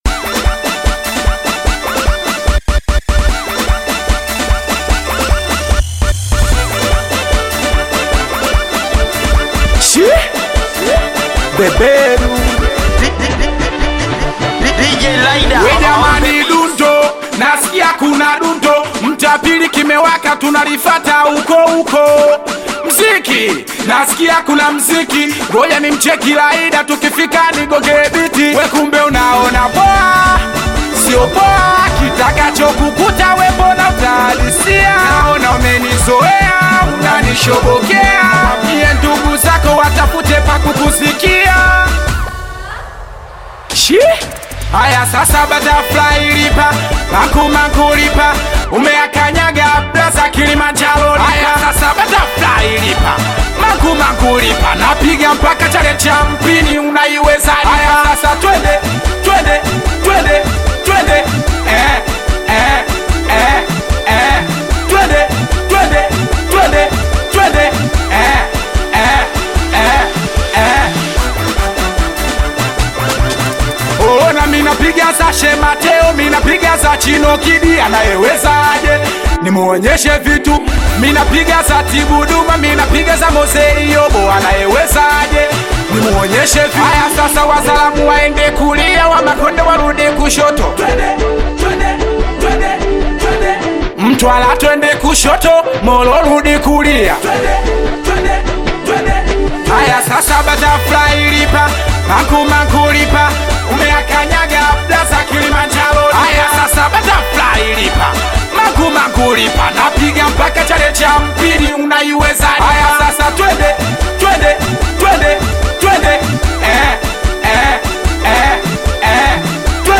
Singeli music track
Tanzanian Bongo Flava
Singeli song